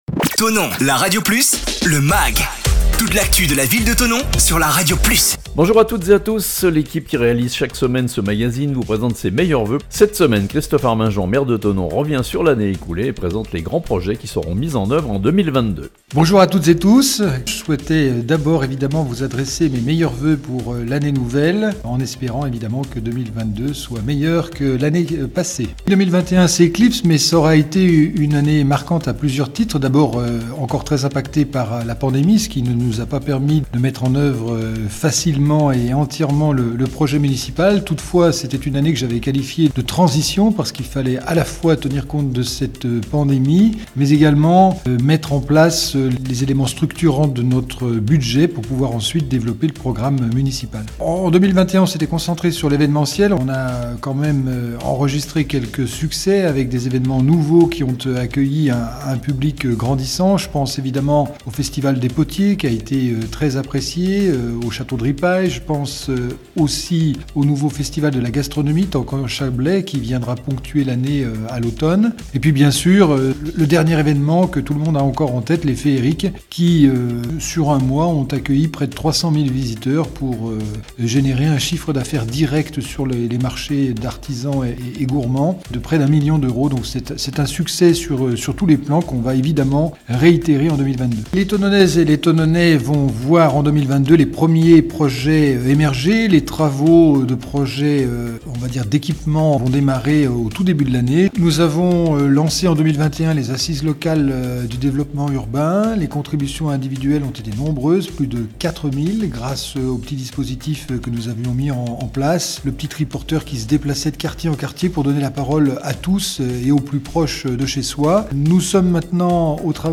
Cette semaine Christophe Arminjon, Maire de Thonon, présente ses vœux pour la nouvelle année et revient sur les événements marquants de l'an dernier dans la ville. Il présente également quelques projets qui devraient être réalisés en 2022.